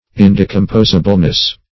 Search Result for " indecomposableness" : The Collaborative International Dictionary of English v.0.48: Indecomposableness \In*de`com*pos"a*ble*ness\, n. Incapableness of decomposition; stability; permanence; durability.